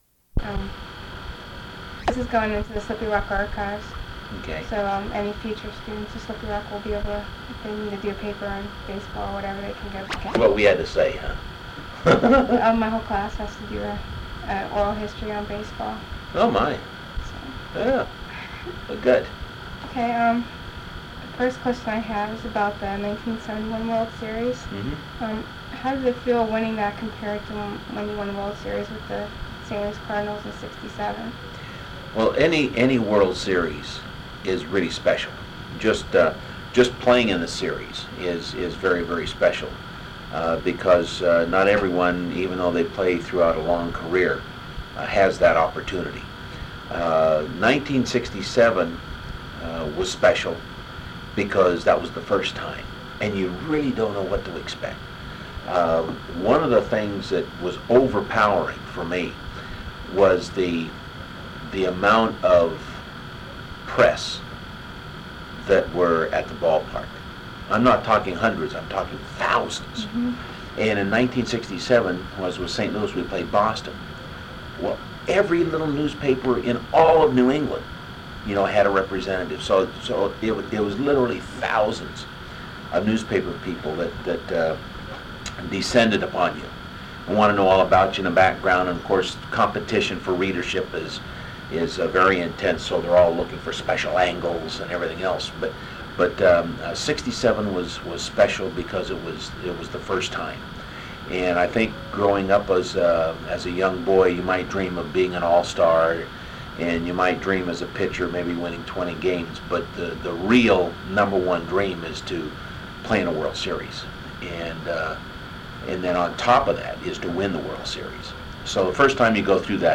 Briles, Nelson Interview Audio